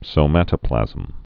(sō-mătə-plăzəm, sōmə-tə-)